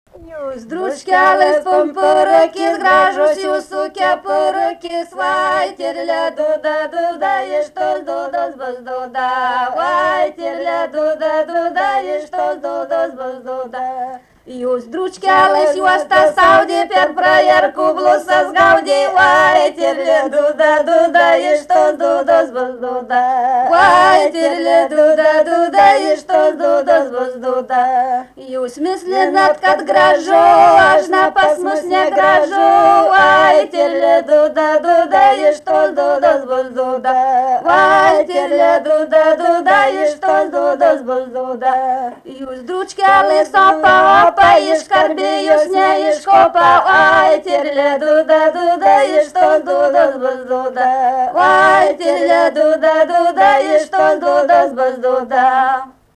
vokalinis
daina, vestuvių